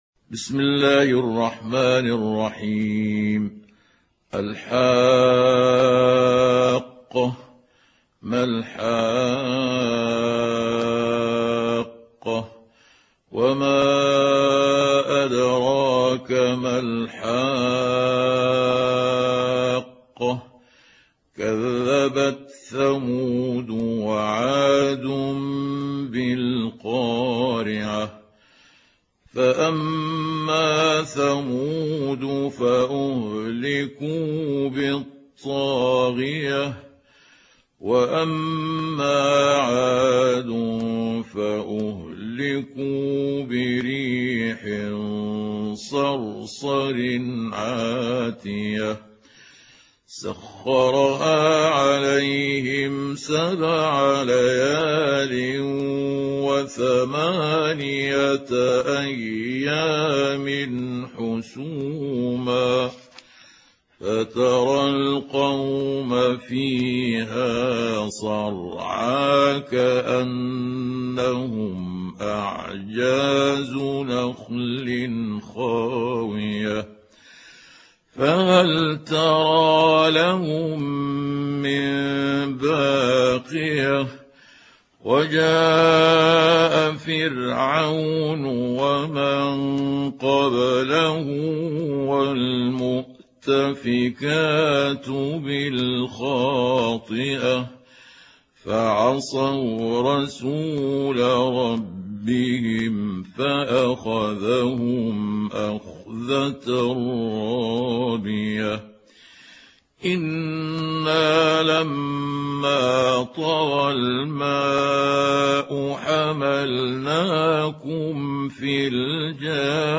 سورة الحاقة | القارئ محمود عبد الحكم